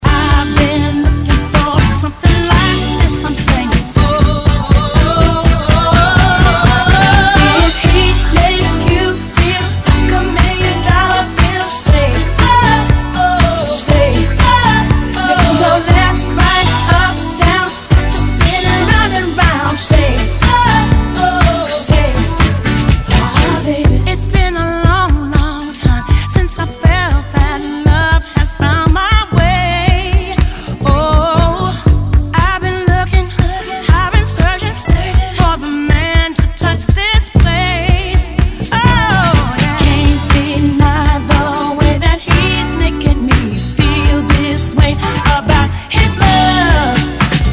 Tag       R&B R&B